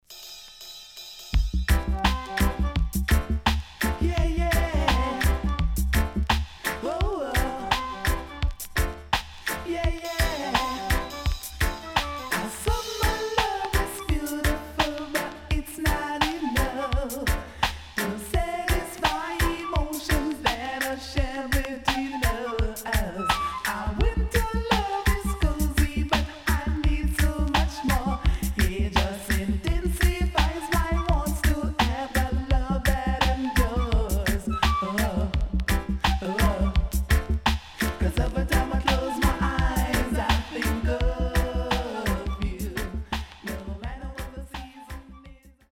HOME > DISCO45 [DANCEHALL]
SIDE A:所々チリノイズがあり、少しプチノイズ入ります。